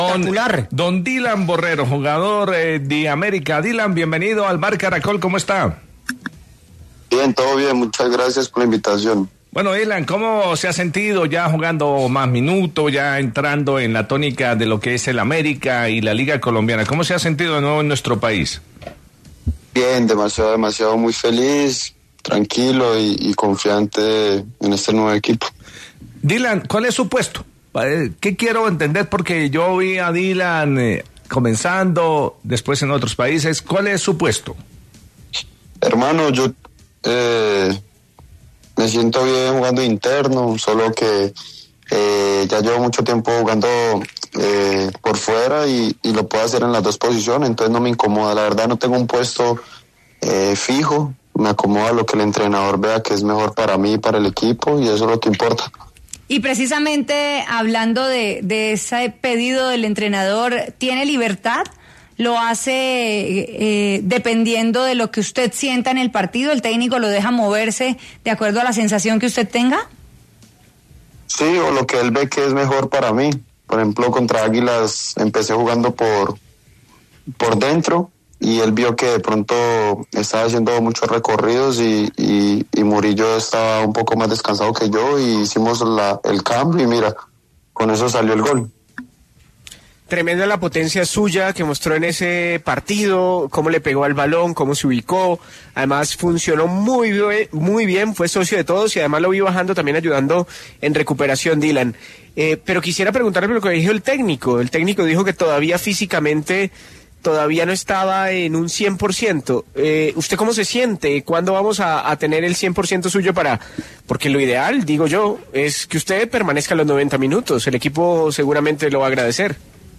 En diálogo con El VBar Caracol, Borrero, que convirtió su primer gol en el triunfo ante Águilas por 2-1, habló de la constante comunicación que mantiene con el técnico Gabriel Raimondi, quien le ha dado plena confianza.